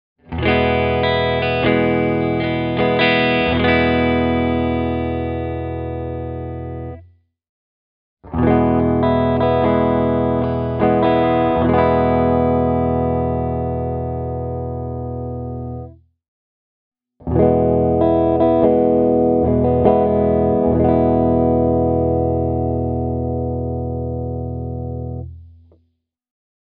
All the following audio clips have been recorded with the tone filter -switch starting at off, then going to moderate roll-off, with the last phrase using the full treble cut selection:
Hagström Northen Swede – neck pickup/clean